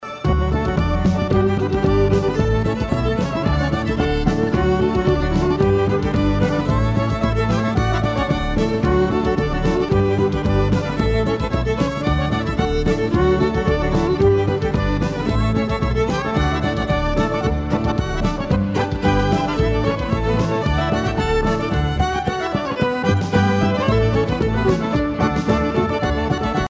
danse : reel
Pièce musicale éditée